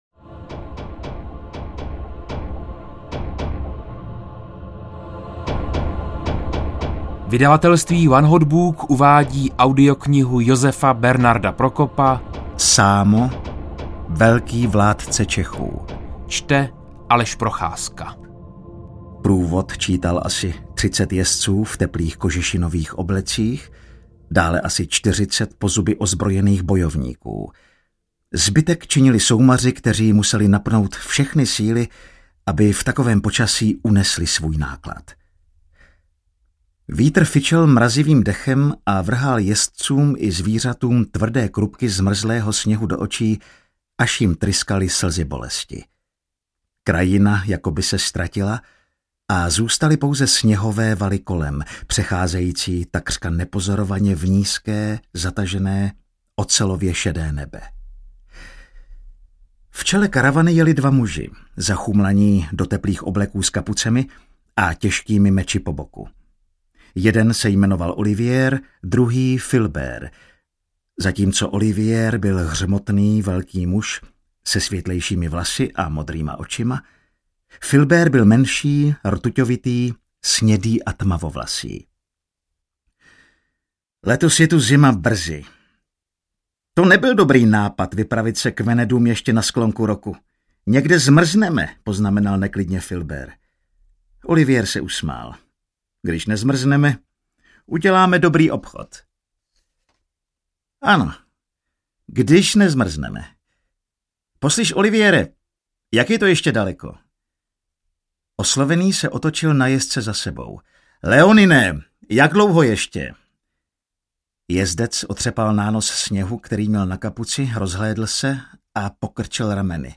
Sámo audiokniha
Ukázka z knihy